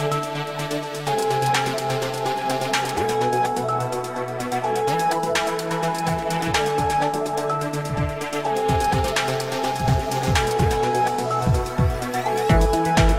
Electro RIngtones